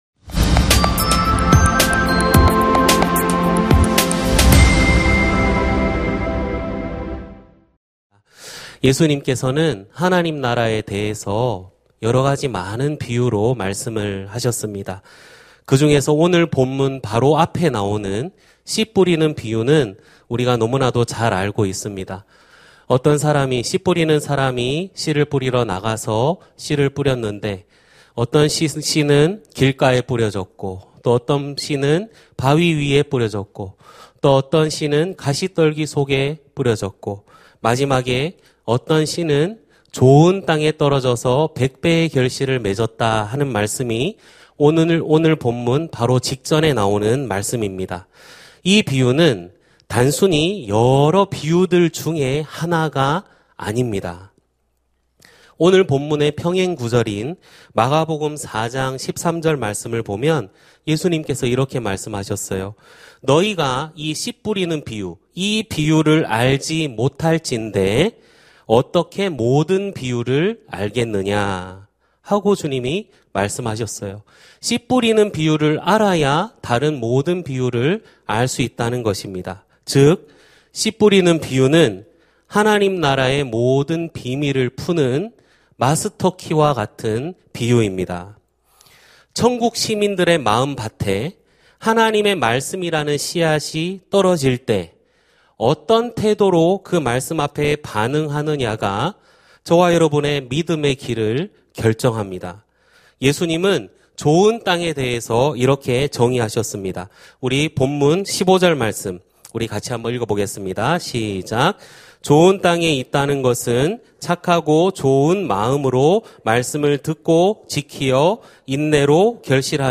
설교 : 수요향수예배 (수지채플) 너희 믿음이 어디 있느냐?